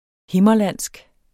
Udtale [ ˈhemʌˌlanˀsg ]